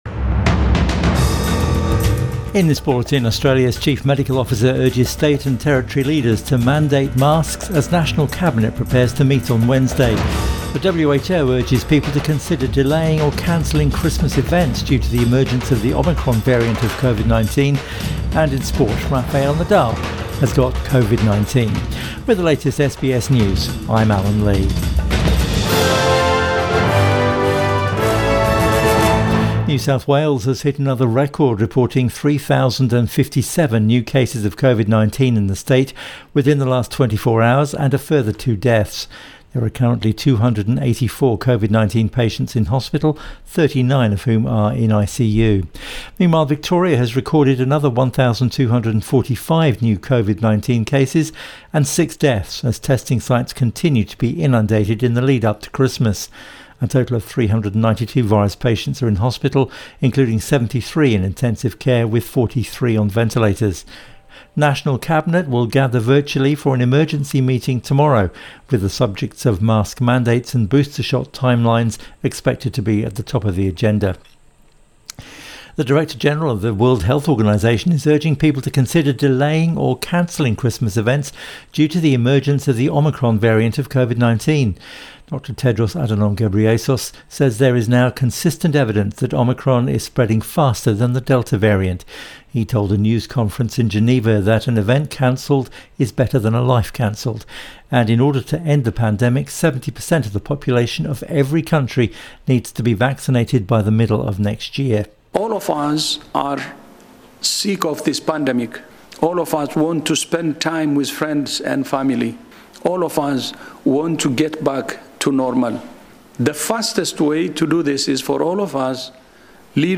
Midday Bulletin 21 December 2021